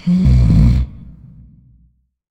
inhale.ogg